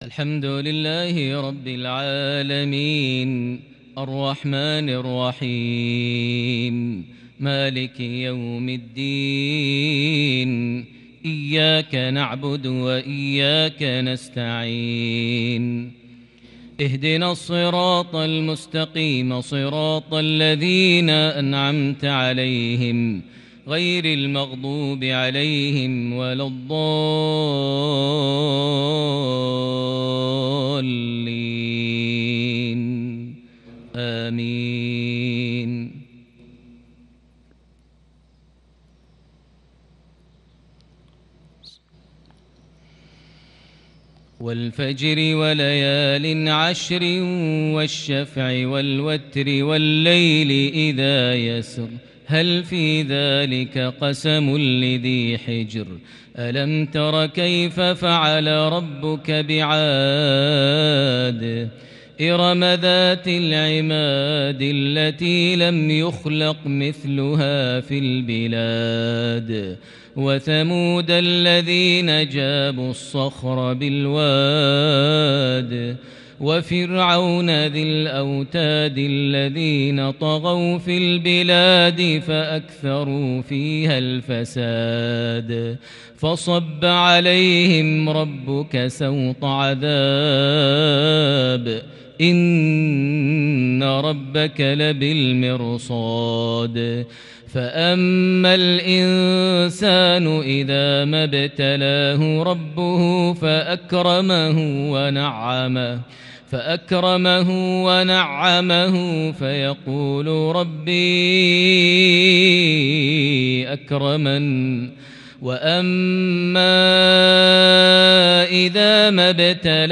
تلاوة فريدة للغاية | من سورة الفجر | مغرب 13 ربيع الثاني 1442هـ > 1442 هـ > الفروض - تلاوات ماهر المعيقلي